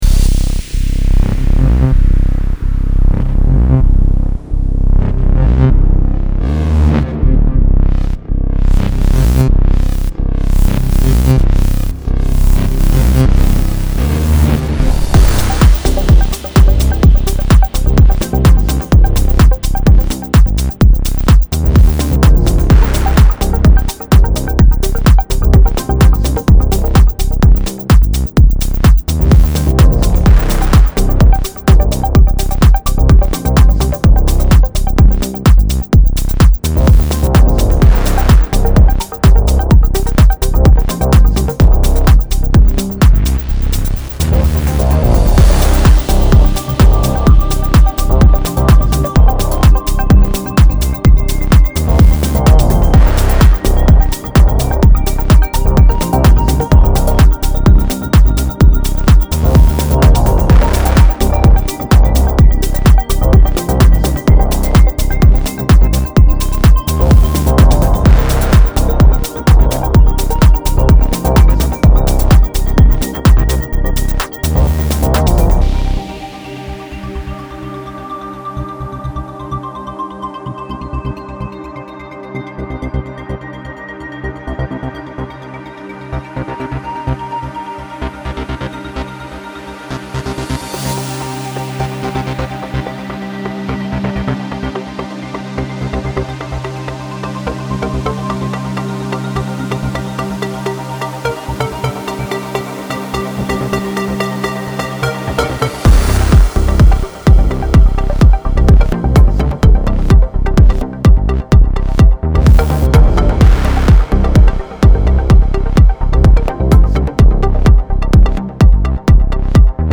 Вот такое техно)
В этот раз меньше обработки. Ее практически нет. А результат намного лучше) Это урезанная версия без интро и конца.